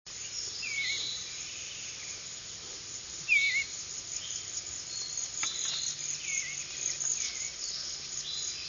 Cedar Waxwing
Matthews Arm Campground, Shenandoah National Park, 7/8/02 (34kb) with Oriole introductory note and Robin scold. Insect like buzz.
waxwings568.wav